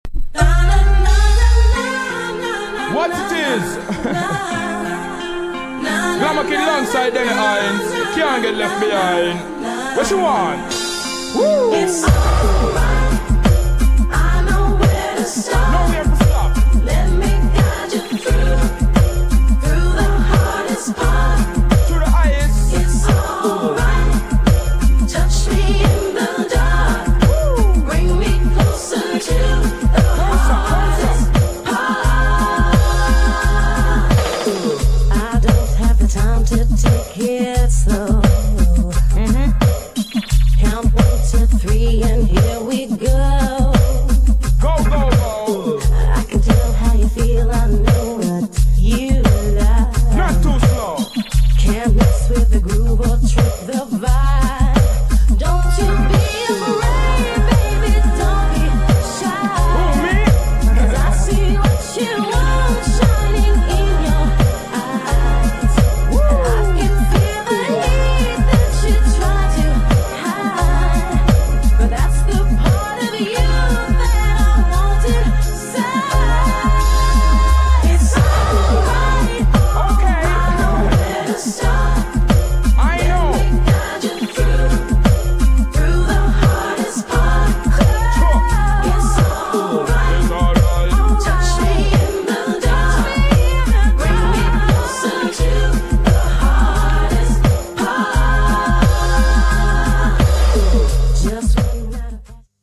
GENRE R&B
BPM 86〜90BPM